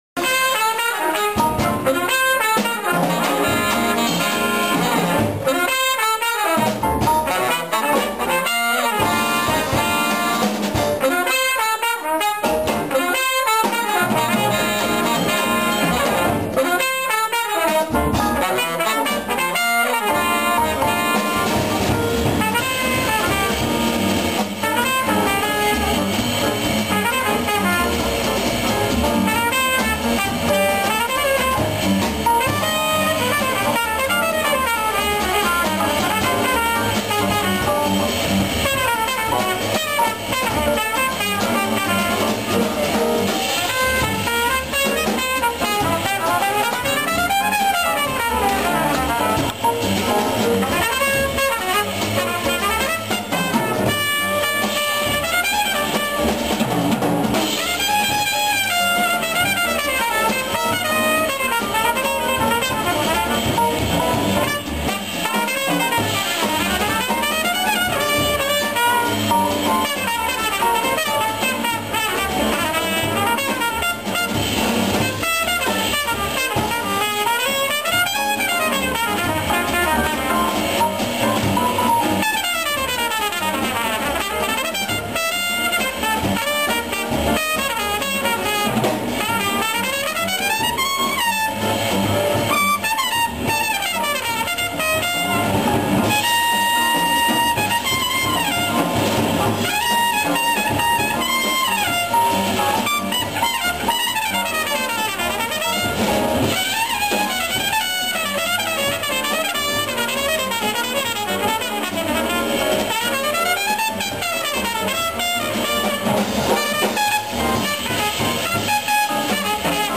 trompette
sax Ténor
piano
contrebasse